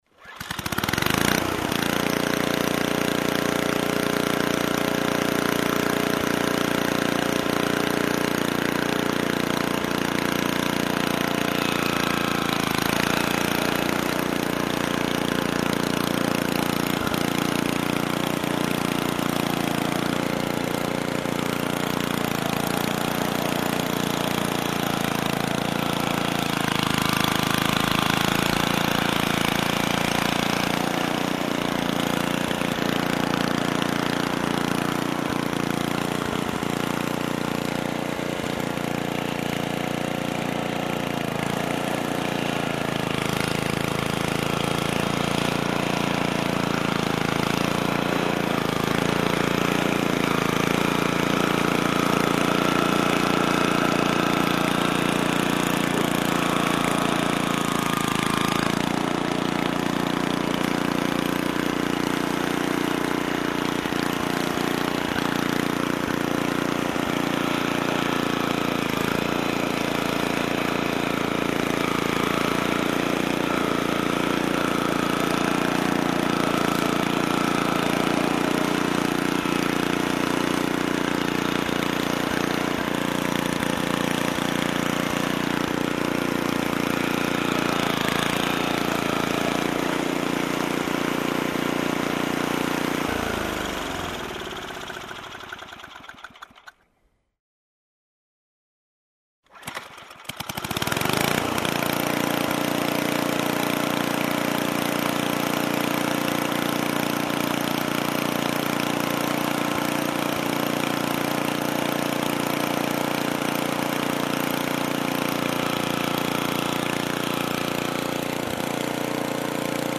Generator, pornire, lucru, blocaj: